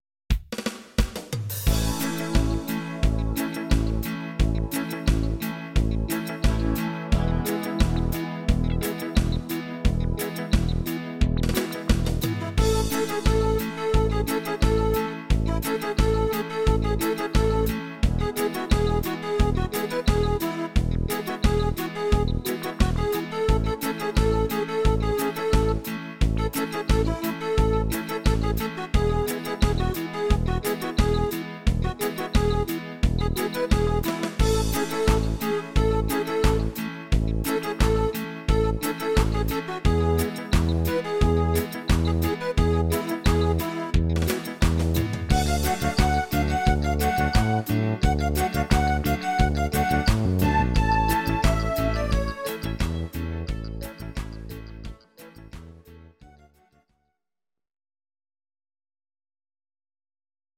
These are MP3 versions of our MIDI file catalogue.
Please note: no vocals and no karaoke included.
reggae version